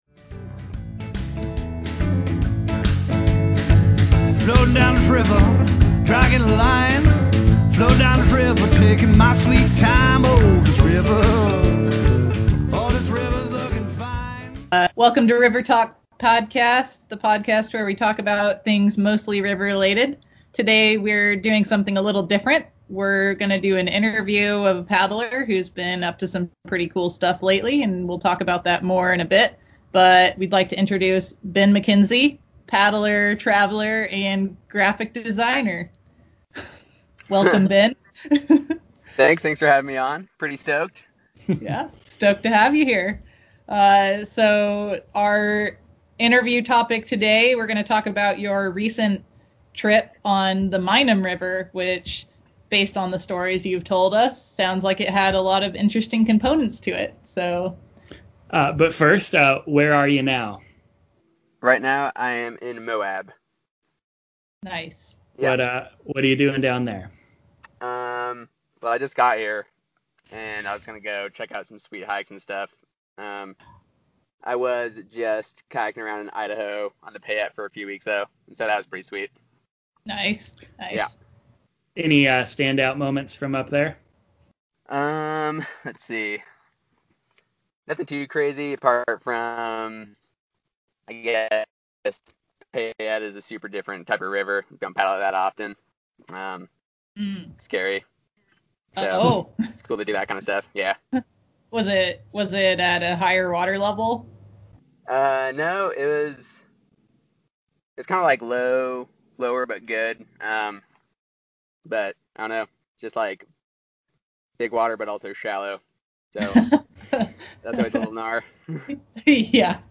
Ep. 89: Interview